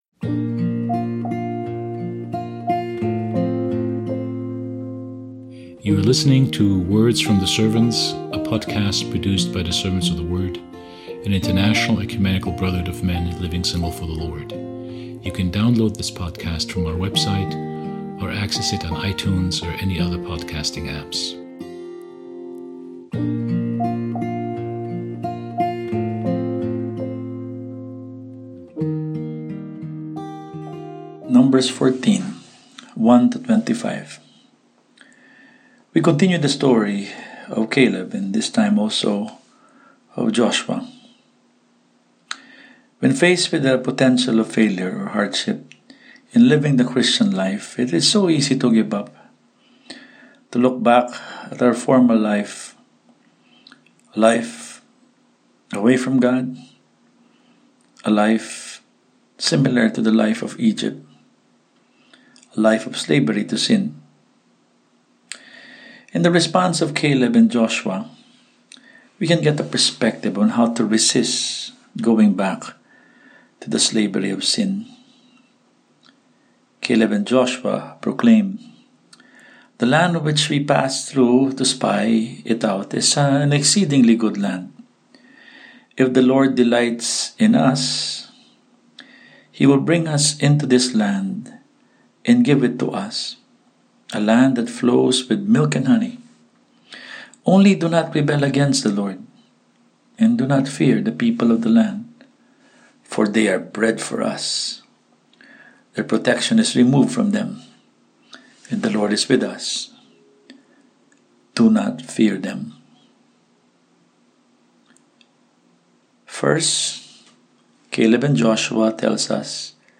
Throughout the 40 Days of Lent, our Words from the Servants podcast will feature brothers from around the world as they give daily commentary on the Pentateuch and the book of Hebrews .